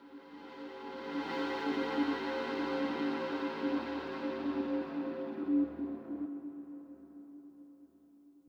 elevator.wav